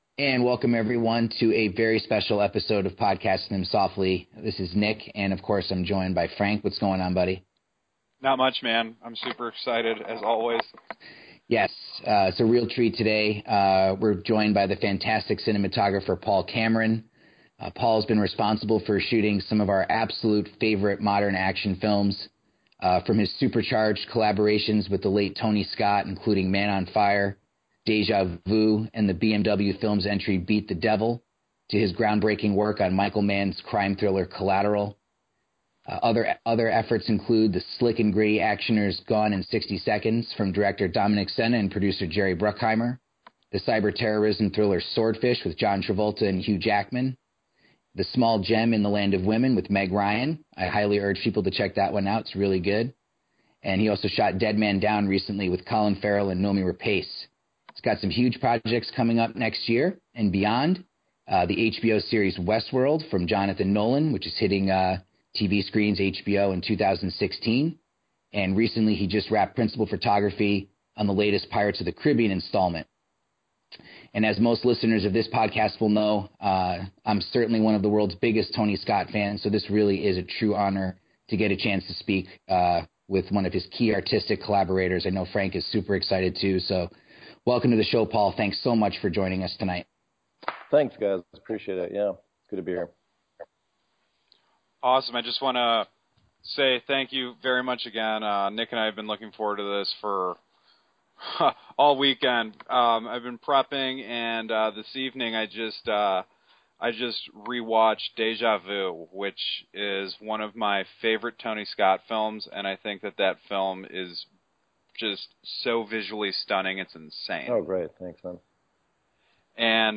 Podcasting Them Softly is extremely honored to present a chat with the fantastic cinematographer Paul Cameron.